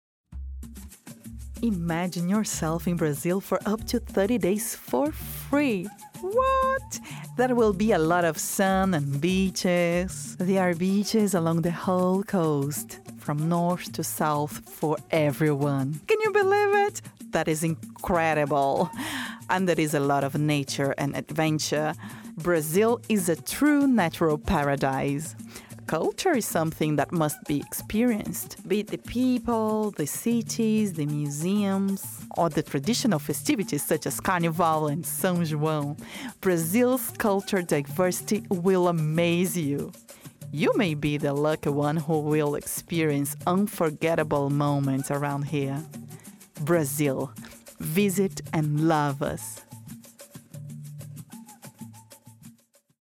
Feminino
Inglês - Reino Unido
Comercial Inglês
Voz Padrão - Grave 00:53